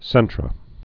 (sĕntrə)